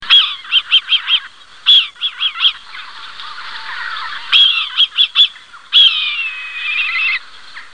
Kania ruda - Milvus milvus
głosy